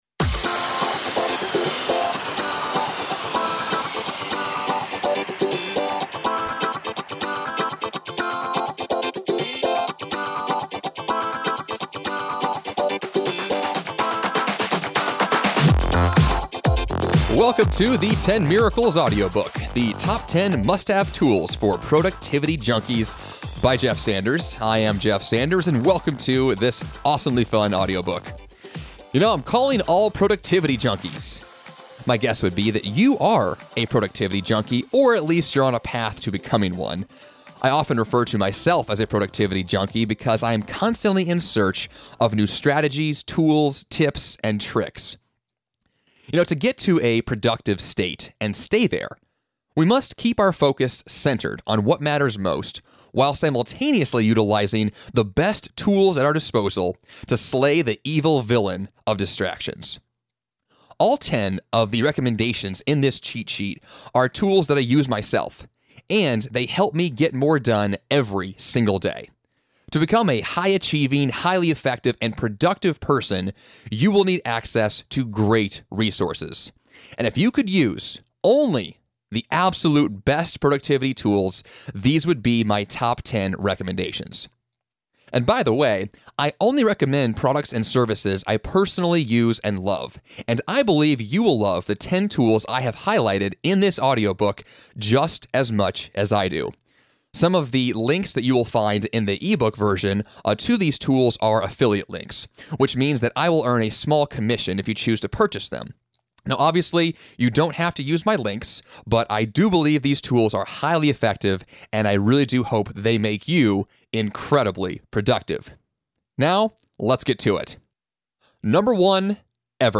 10 Miracles Audiobook